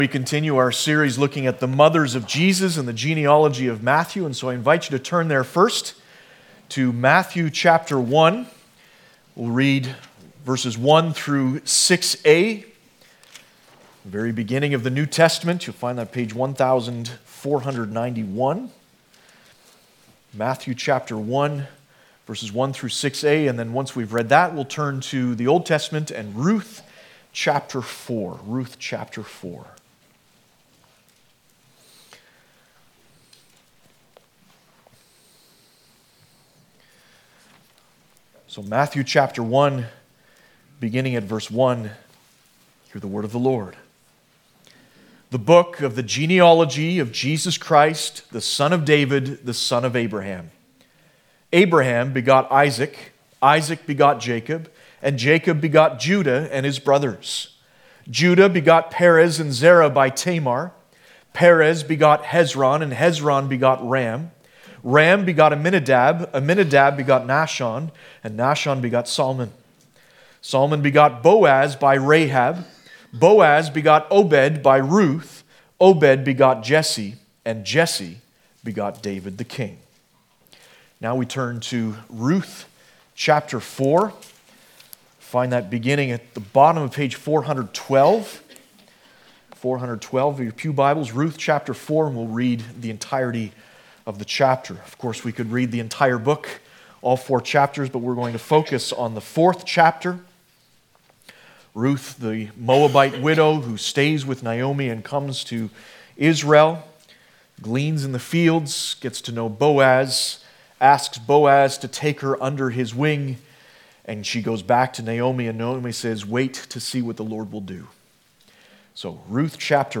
Passage: Ruth 4 Service Type: Sunday Morning